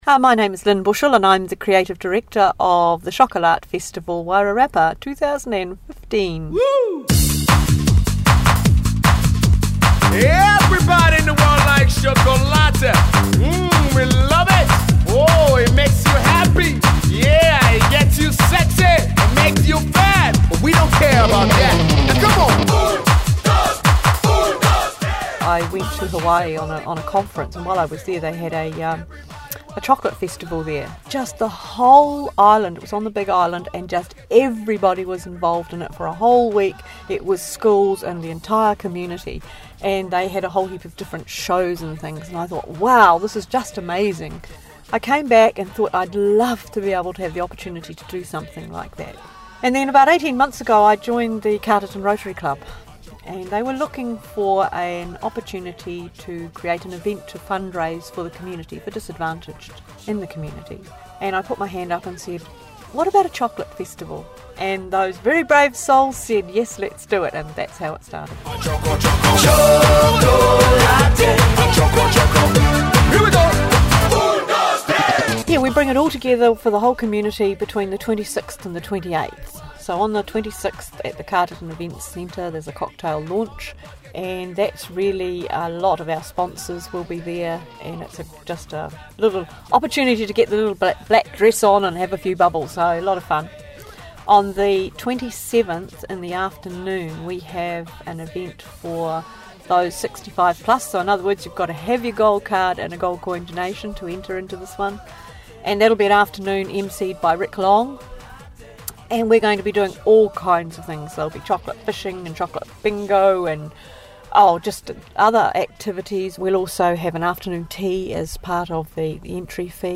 Below is a cheeky little audio featurette